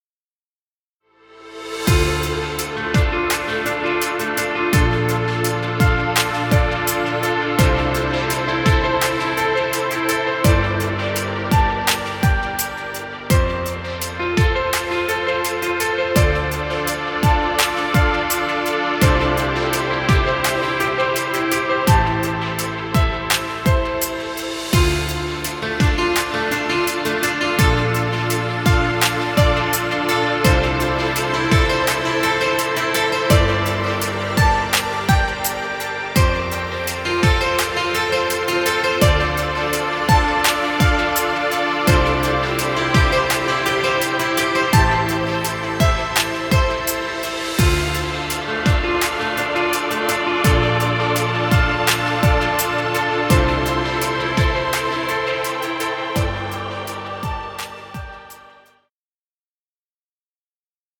Chillout music. Background music Royalty Free.